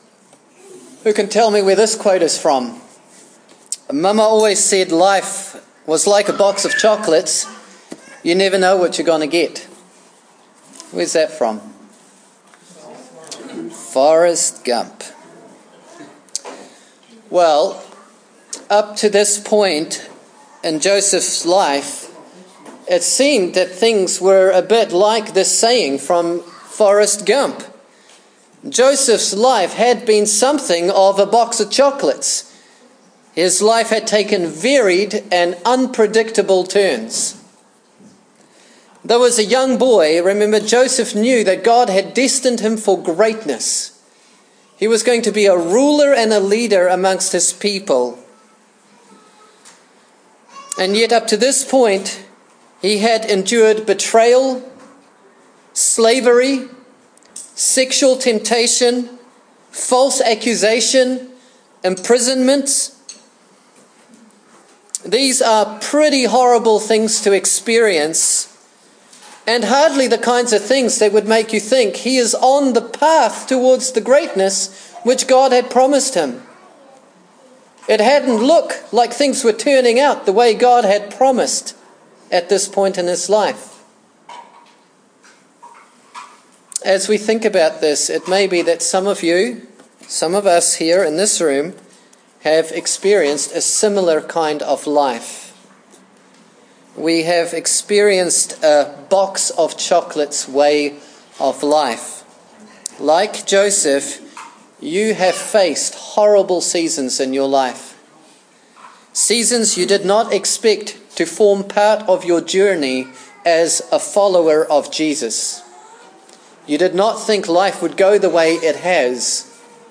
Cultivating Faithfulness | Genesis 40 (Poor Audio Quality)